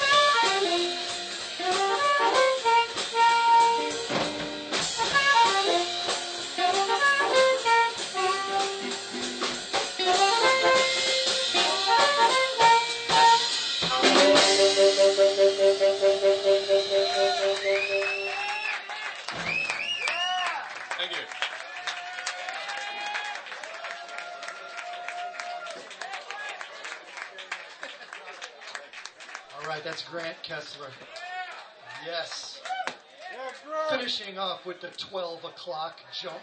5th OTS Recital - Winter 2005 - rjt_4126